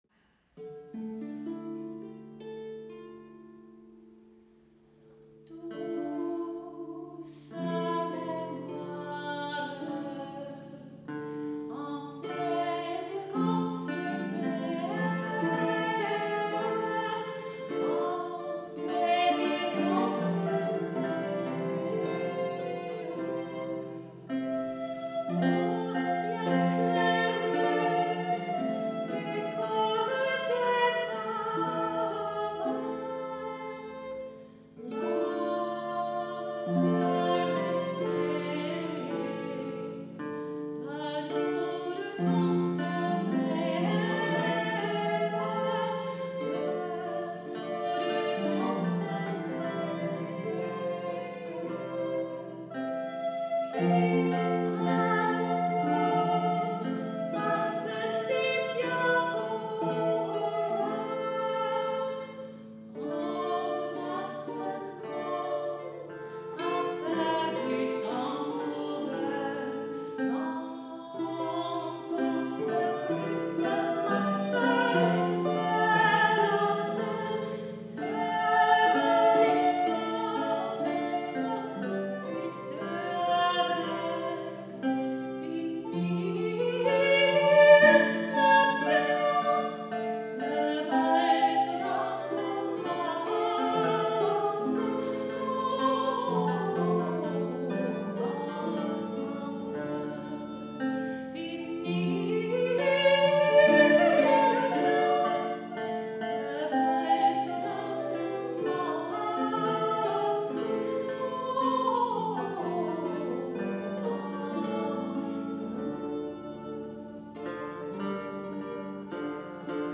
Die Stücke spiegeln höfische Liedkultur des 16. und 17. Jahrhunderts: